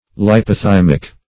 Search Result for " leipothymic" : The Collaborative International Dictionary of English v.0.48: Leipothymic \Lei`po*thym"ic\ (l[imac]`p[-o]*th[i^]m"[i^]k), a. See Lipothymic .
leipothymic.mp3